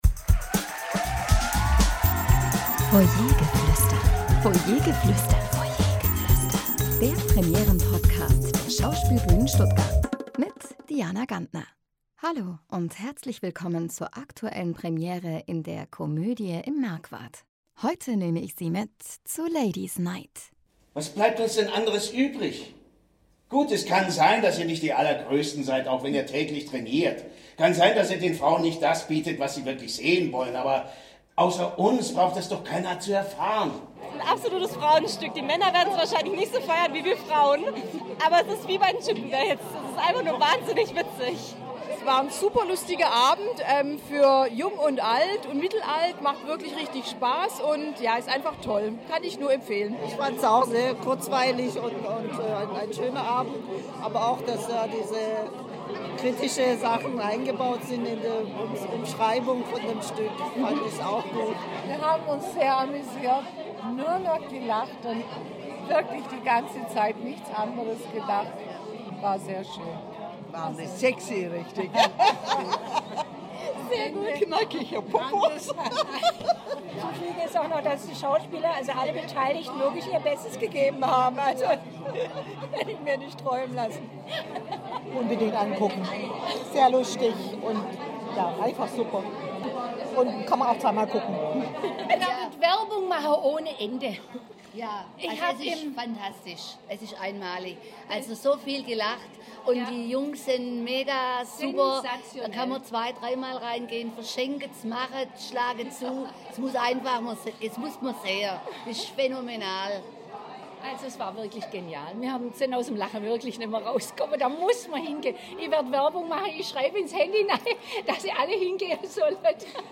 Publikumsstimmen zur Premiere von “Ladies Night”